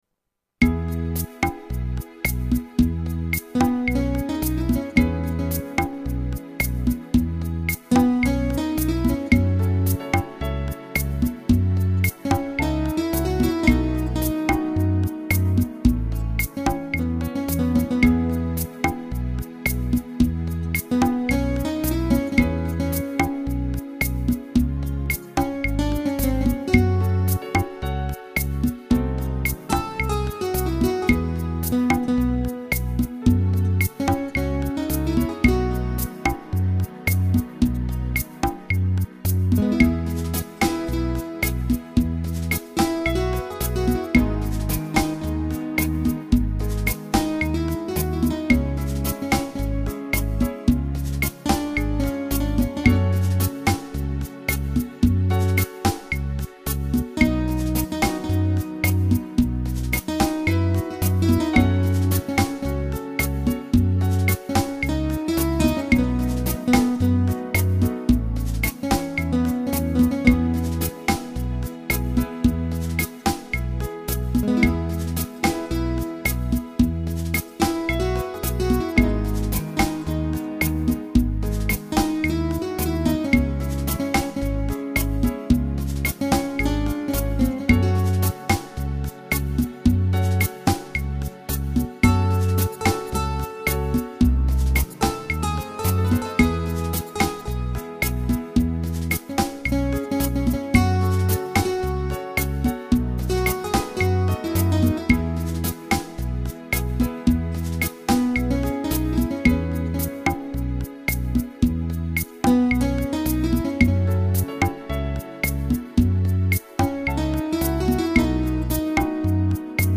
Arrangiamenti didattici di brani d'autore